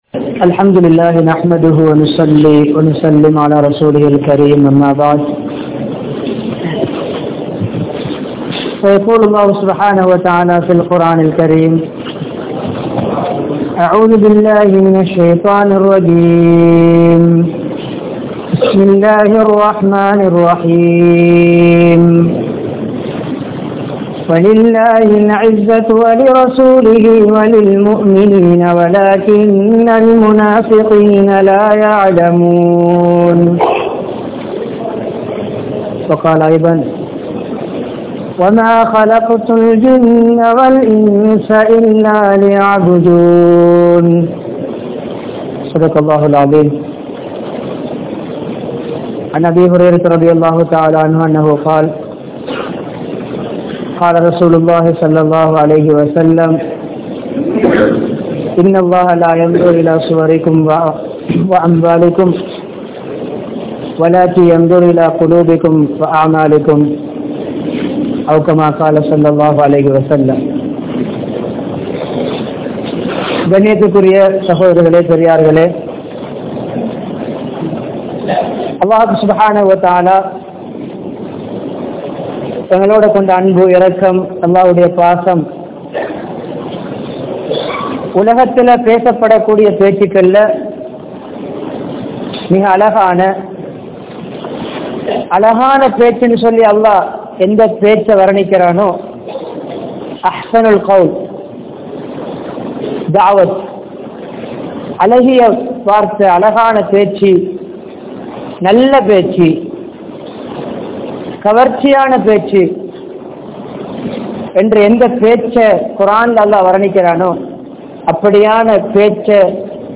Vaalkaiel Veattri Veanduma? (வாழ்க்கையில் வெற்றி வேண்டுமா?) Jumua Night Bayan | Audio Bayans | All Ceylon Muslim Youth Community | Addalaichenai